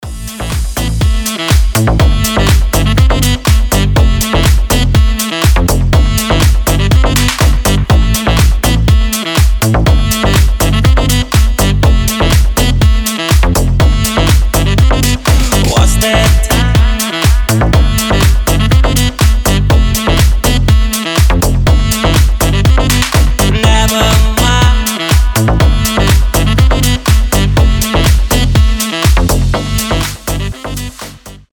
• Качество: 320, Stereo
мужской голос
deep house
Саксофон
Стиль: indie dance, nu disco